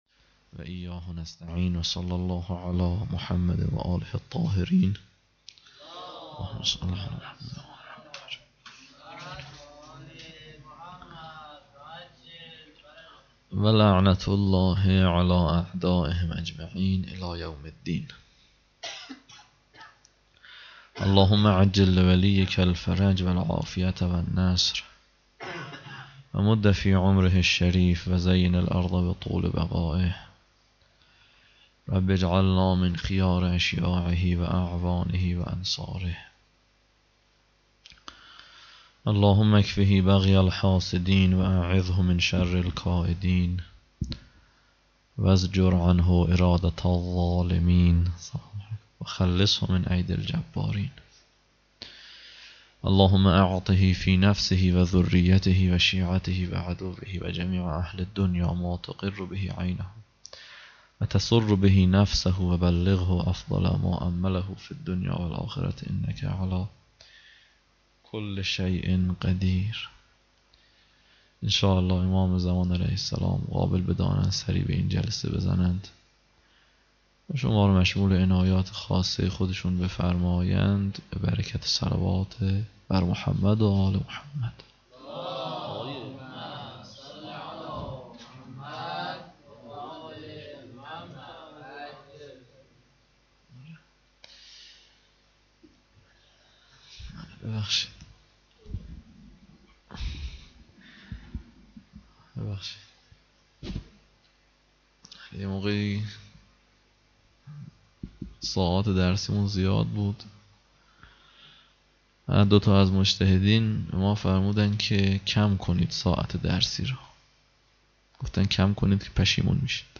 شب دوم محرم 91 - هیات لثارات الحسین - حوزه نخبگان
00-sokhanrani4.mp3